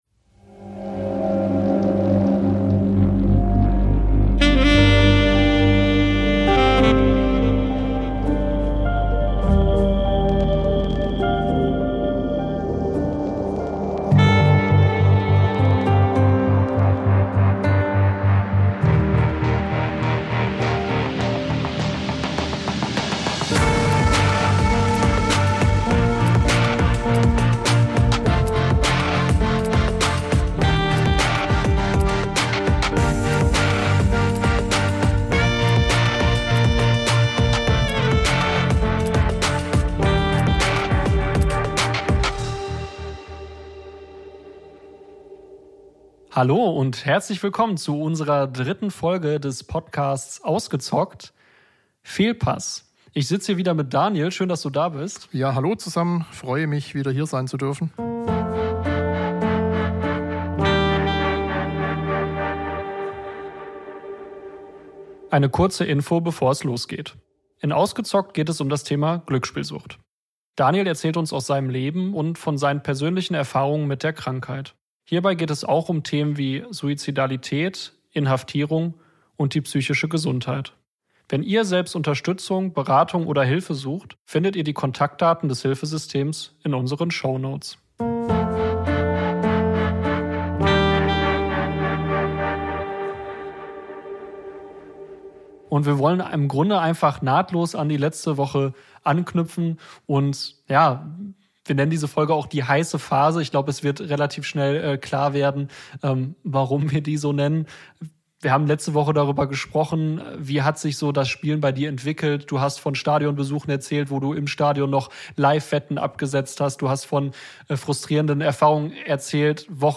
Folge 3: Die heiße Phase ~ Ausgezockt: Sucht ungefiltert - Betroffene im Gespräch Podcast